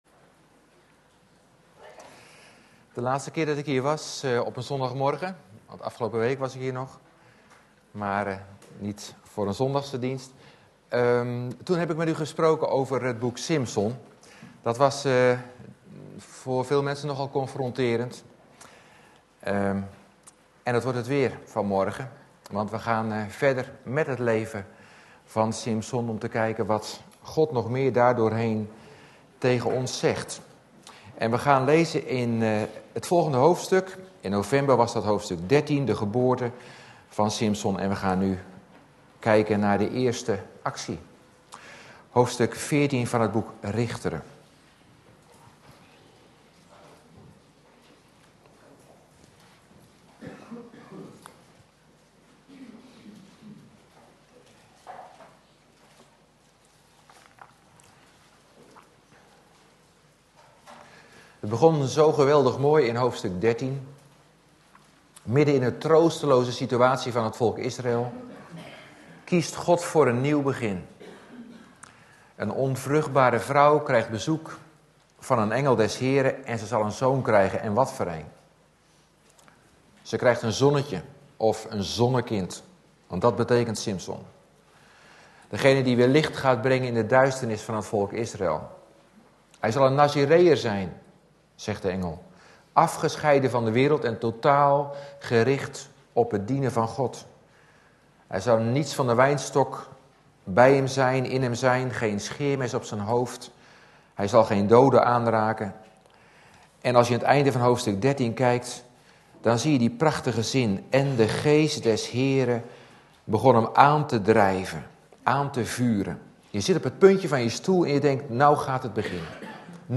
Deze preek is onderdeel van de serie: "Het leven van Simson"